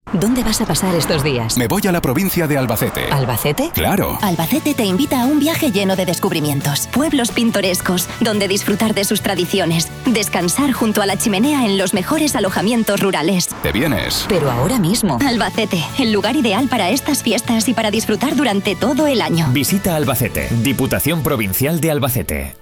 Cuña de radio
ALBACETE_TURISMO_NAVIDAD_Alojamientos_Rurales_v2_(2).wav